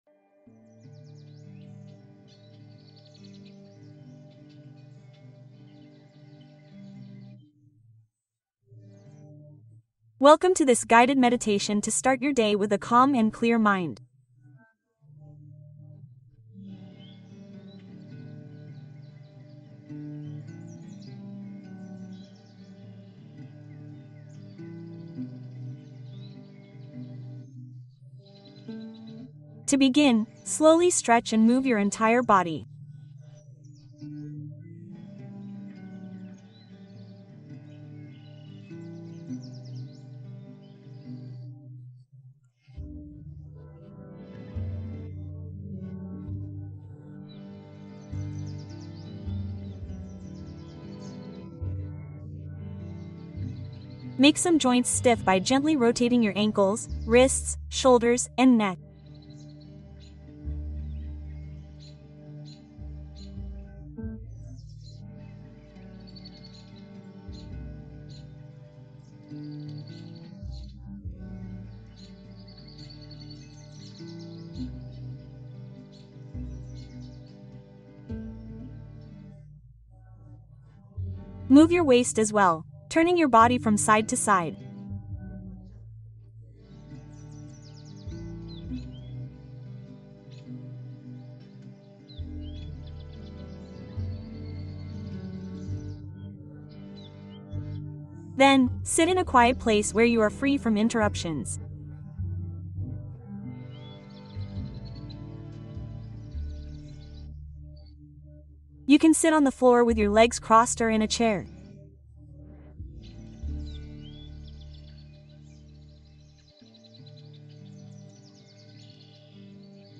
Meditación para principiantes ❤ 12 minutos para conectar contigo